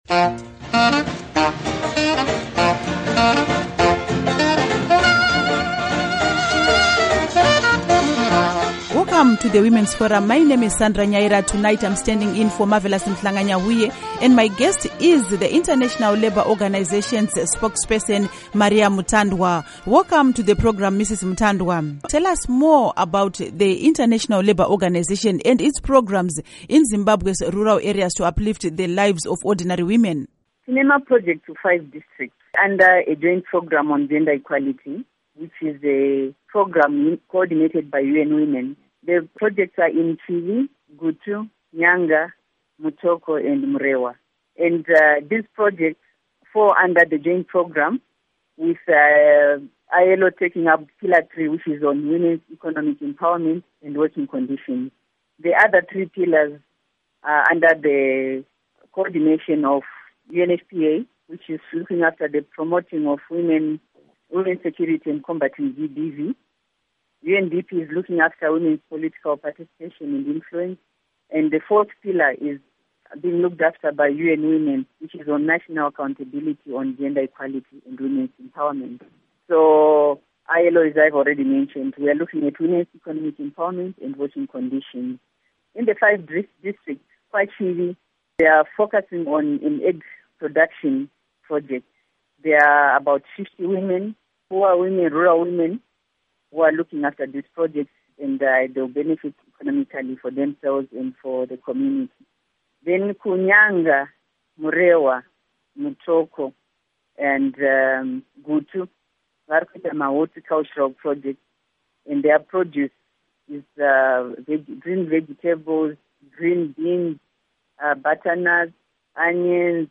WOMEN'S FORUM: Interview